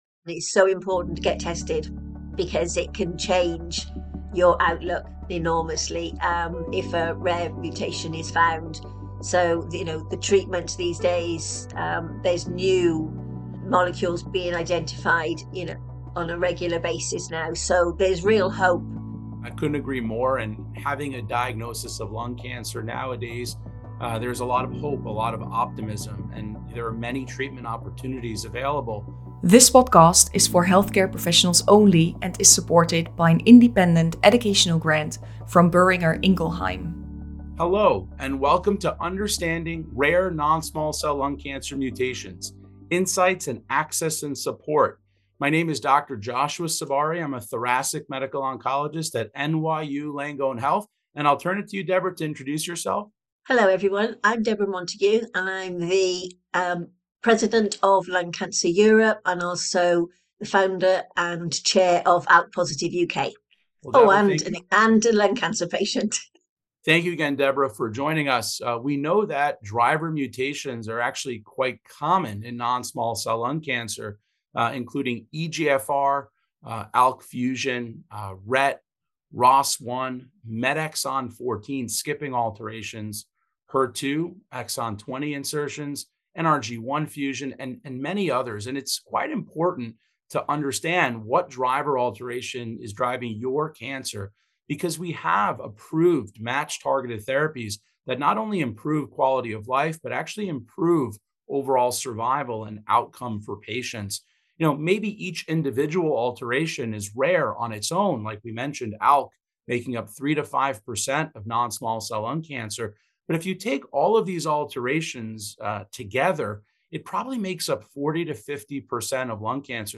Video Podcast